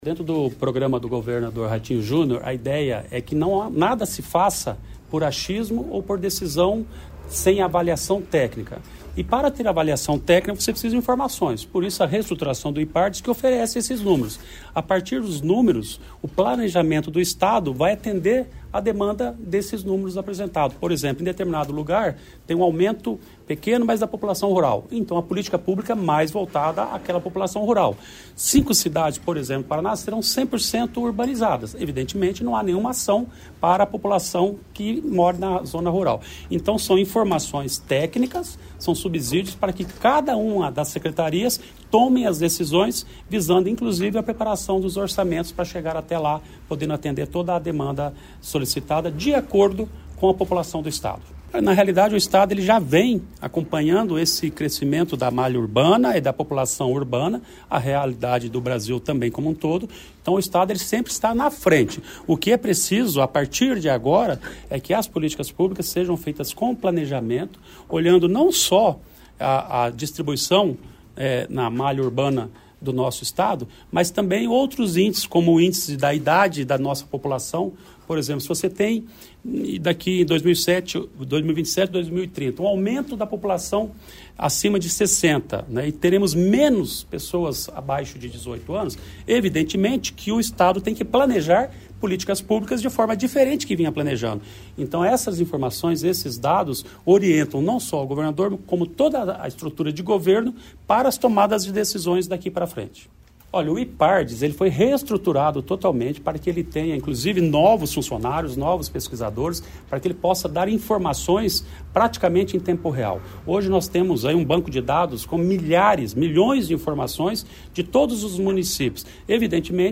Sonora do secretário Estadual do Planejamento, Ulisses Maia, sobre a Projeção Populacional Urbana e Rural dos Municípios Paranaenses